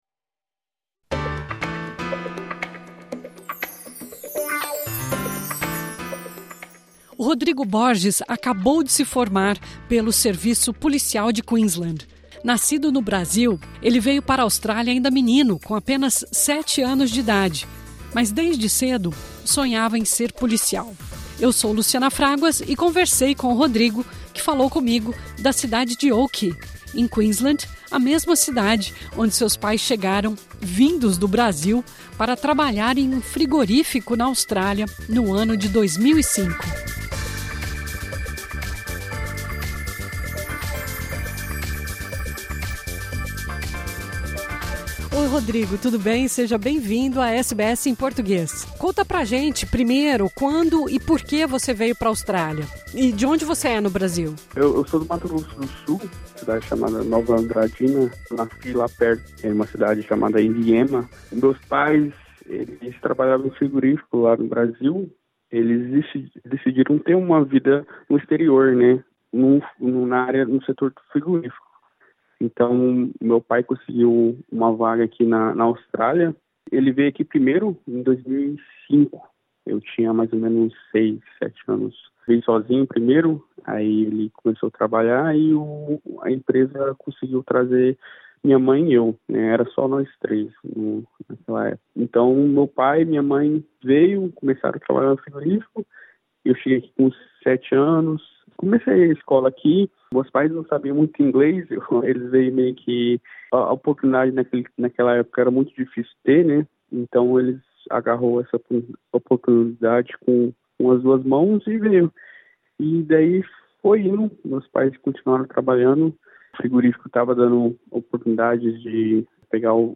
Nesse entrevista ele conta como foi o processo para se tornar um policial na Austrália.